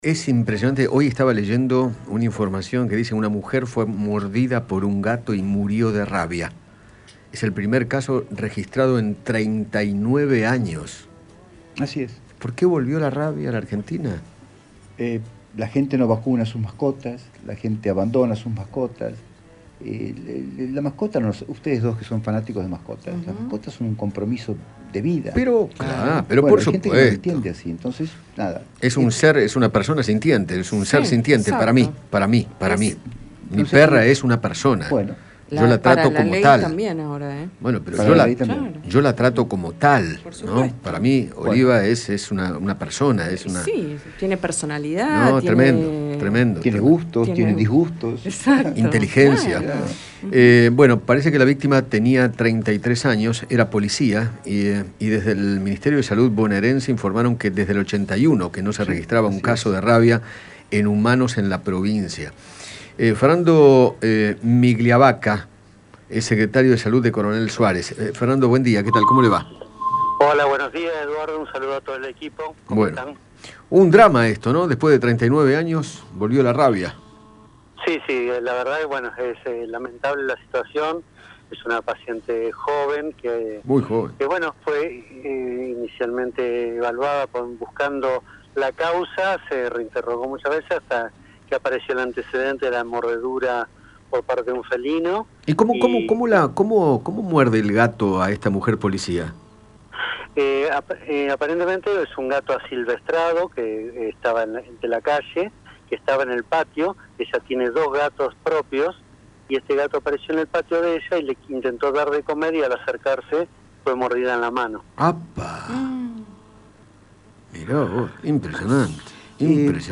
Fernando Migliavacca, secretario de Salud de Coronel Suárez, habló con Eduardo Feinmann sobre la primera muerte de rabia en el país, tras 13 años sin registrarse, y detalló los síntomas que padeció la mujer.